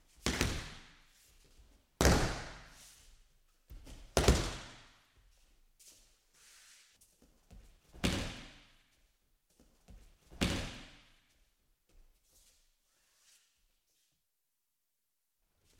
На этой странице собраны звуки, связанные с дзюдо: крики соперников, шум татами, команды тренера.
Звуки падений на татами в соревнованиях по дзюдо